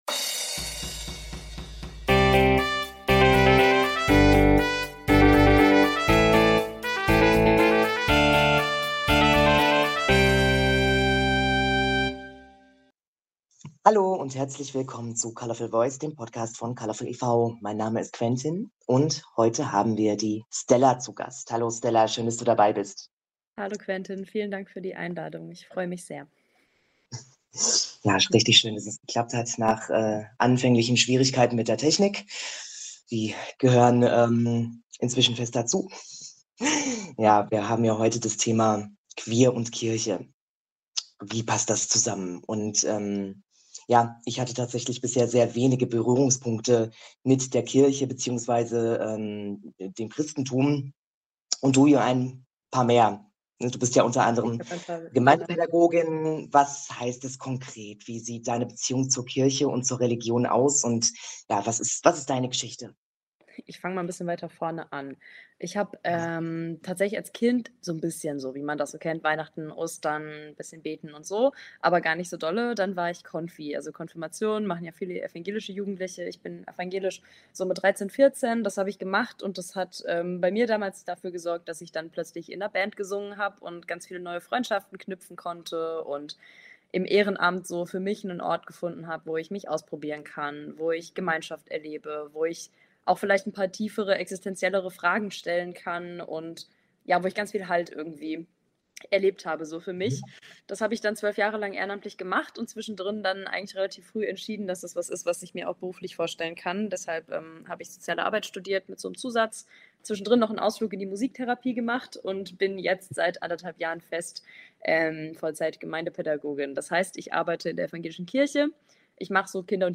Bei unserem Podcast geht es um No-Go Themen, um aktuelles und auch um die Themen, die euch Interessieren. Mit Gästen aus allen Bereichen schaffen es unsere drei Moderator*innen euch unseren Podcast Colorfulvoice einmal im Monat darzubieten.